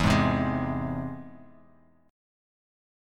E+ Chord
Listen to E+ strummed